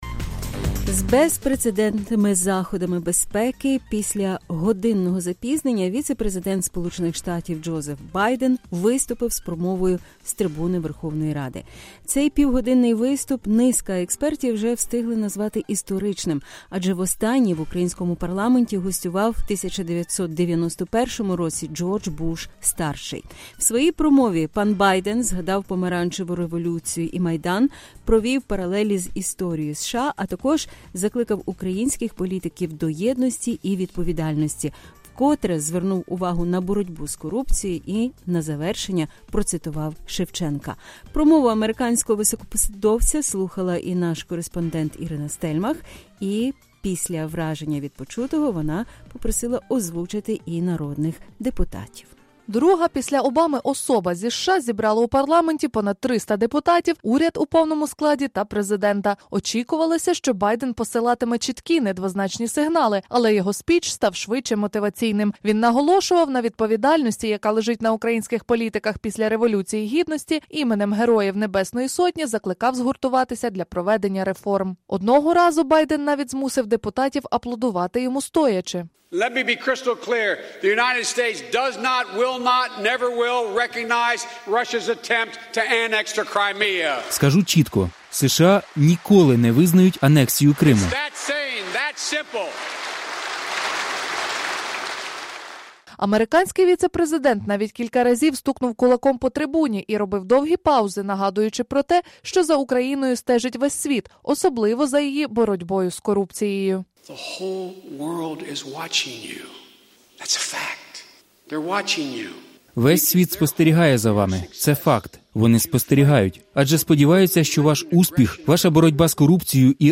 Віце-президент США Джозеф Байден під час виступу з промовою у Верховній Раді. Київ, 8 грудня 2015 року